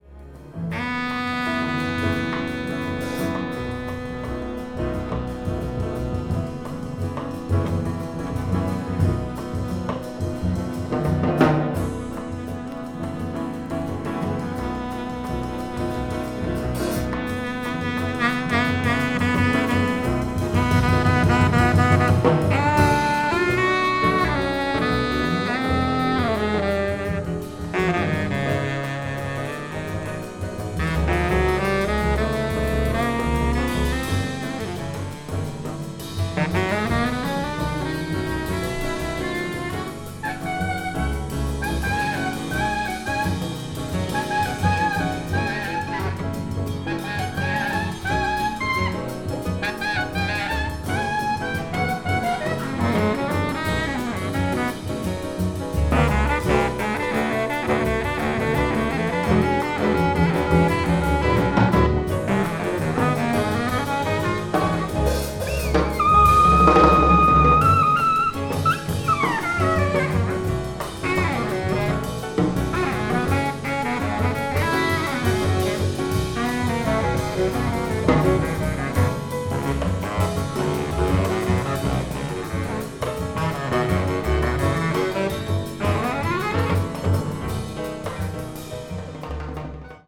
avant-jazz   free improvisation   free jazz   spiritual jazz